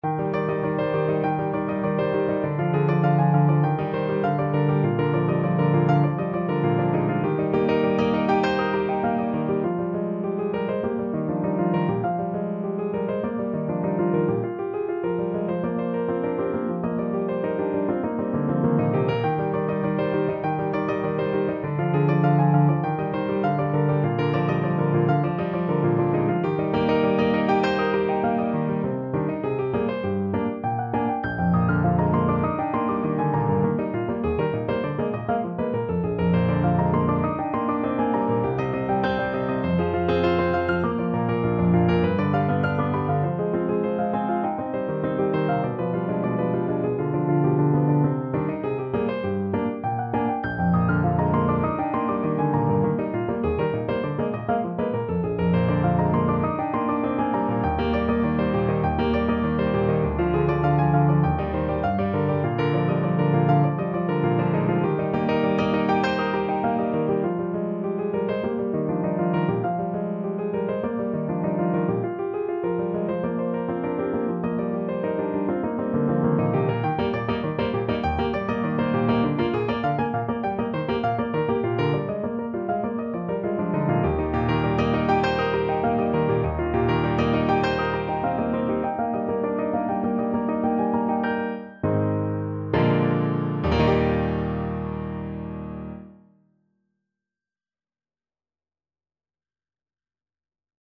Some piano pieces